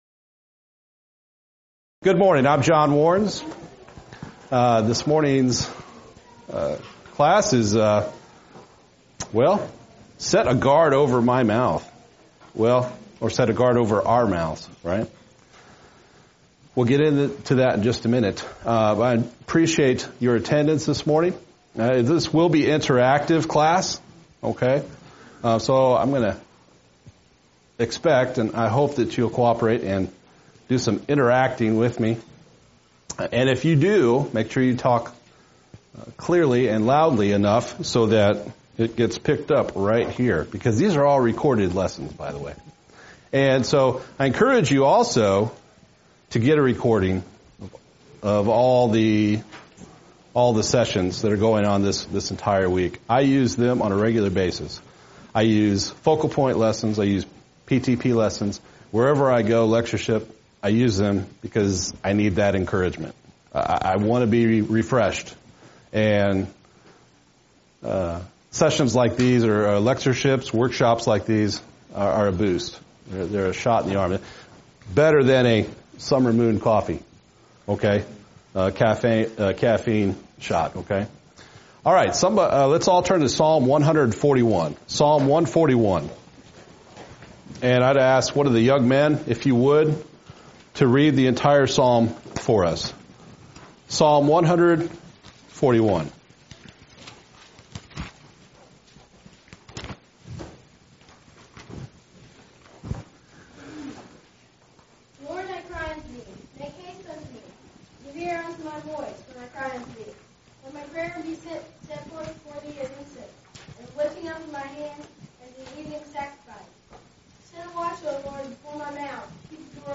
Speech
lecture